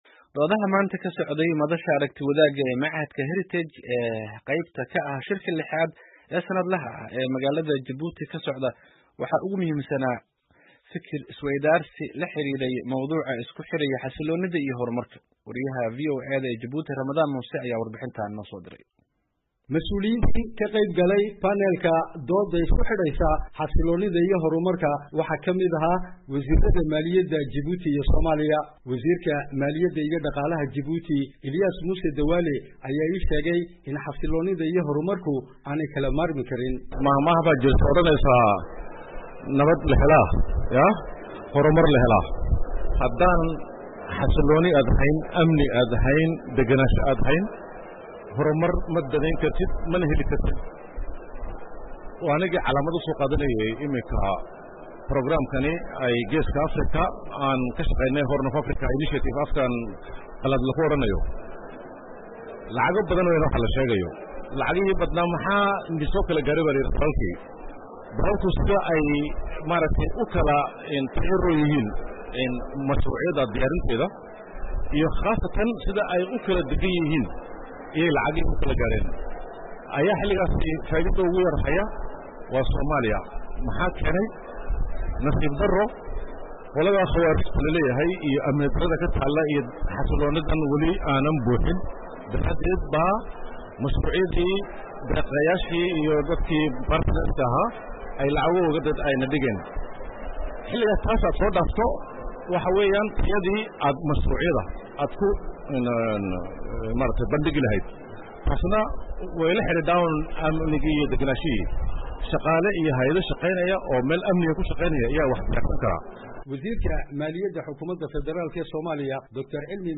Warbixin ku saabsan shirkii Heritage ee maanta